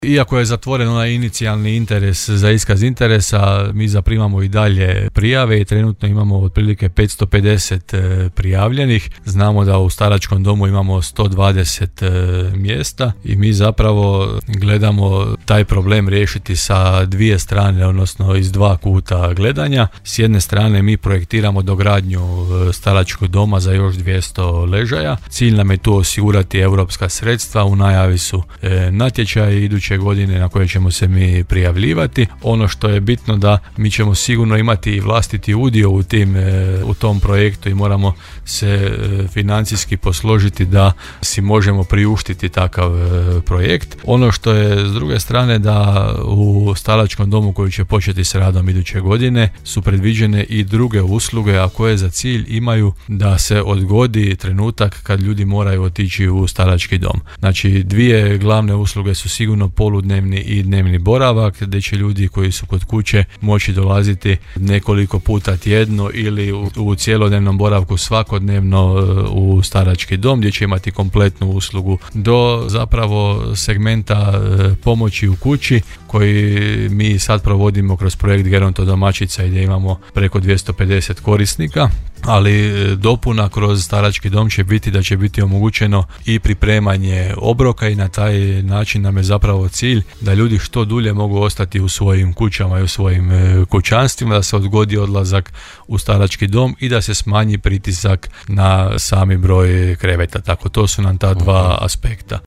Pokretanje rada nove Ustanove staračkog doma u Đurđevcu će uz dogradnju Osnovne škole biti druga najveća ustanova u gradu sa 60-ak zaposlenih odmah u startu, kazao je to u emisiji Gradske teme u programu Podravskog radija gradonačelnik Grada Đurđevca Hrvoje Janči.